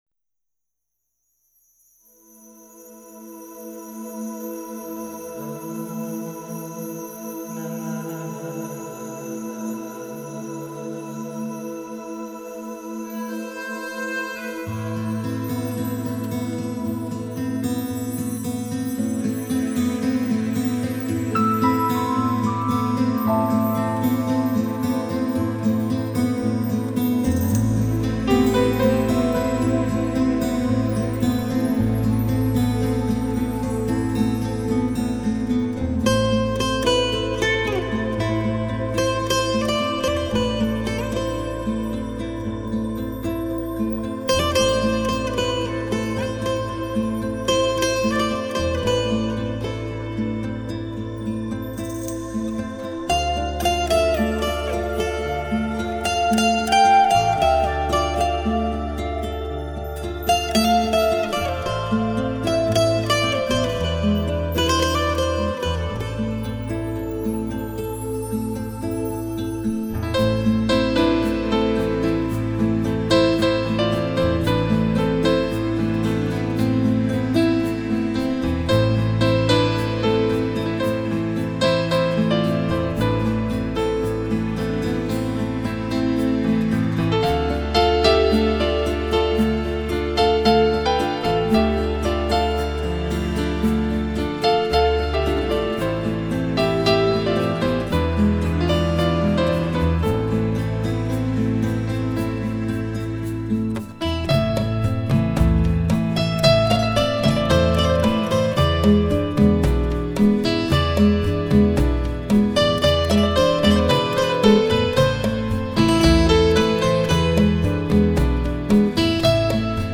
Звучание его гитары уникально!